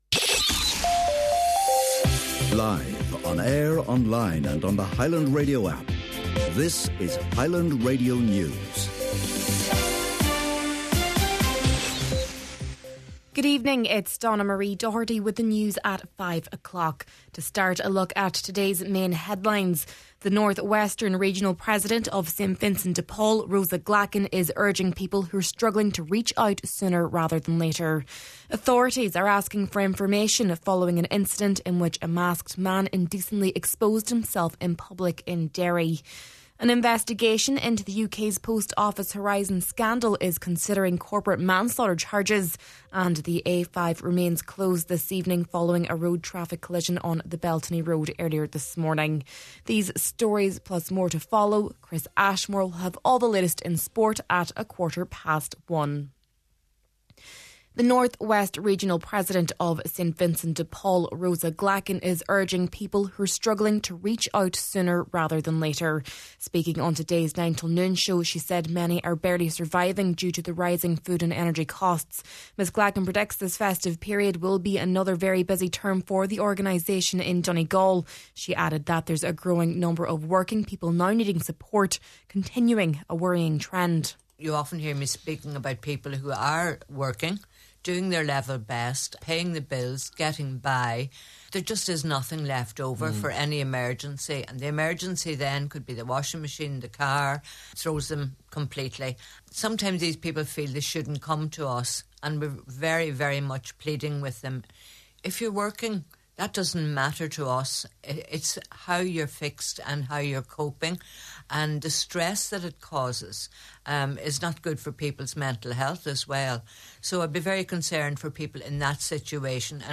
Main Evening News, Sport, and Obituary Notices – Monday, December 1st